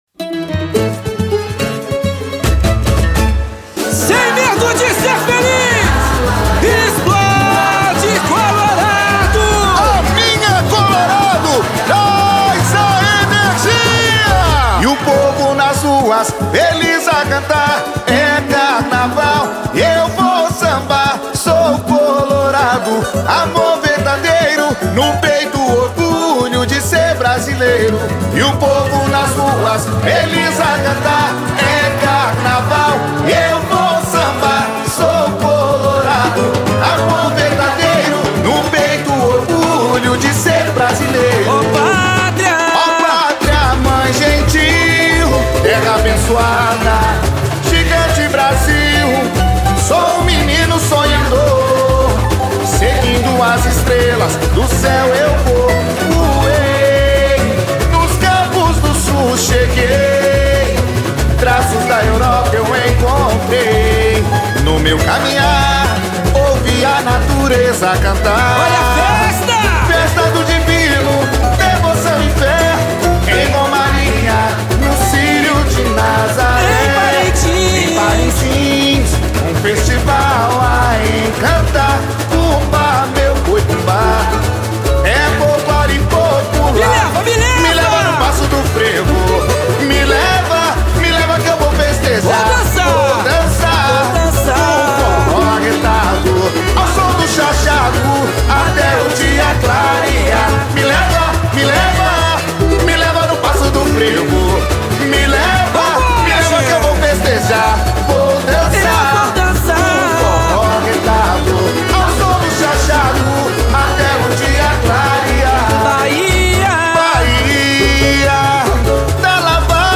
Aliás, a faixa toda é muito bem produzida e empolgante.